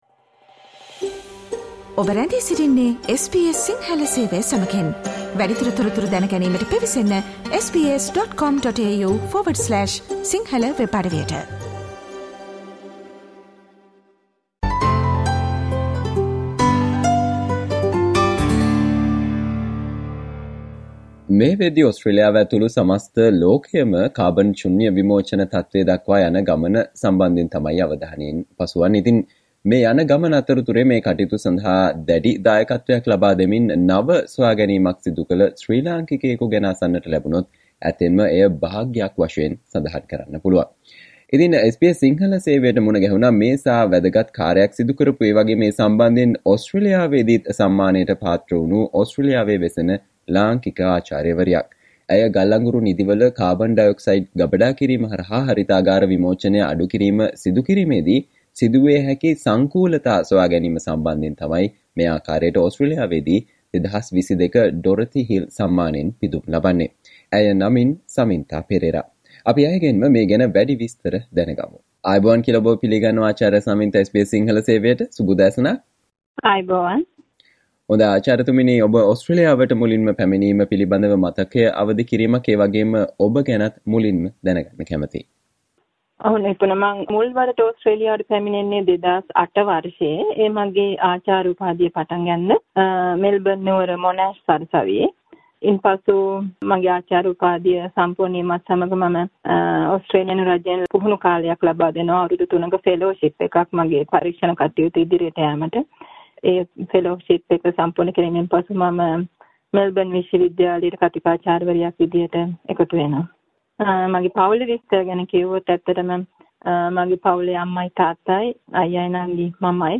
සාකච්චාව